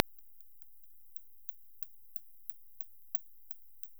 Large-eared Pied Bat – Full spectrum bat calls, NSW, Australia
Large-eared Pied Bat
Can often easily be identified by the alternated pattern of calls between 20 and 30 kHz.
When listening, you can hear a very distinct “attack” in the calls.
The time expanded recordings sounds also very hard and distinct.
This is a typical sequence from a searching bat: